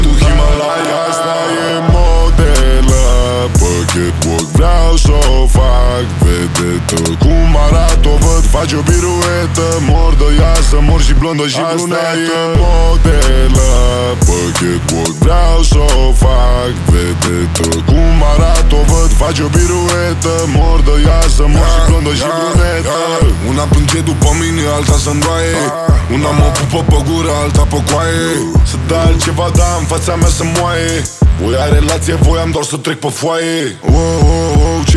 Жанр: Хип-Хоп / Рэп
Hip-Hop, Rap